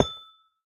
Minecraft Version Minecraft Version 1.21.5 Latest Release | Latest Snapshot 1.21.5 / assets / minecraft / sounds / block / amethyst / place2.ogg Compare With Compare With Latest Release | Latest Snapshot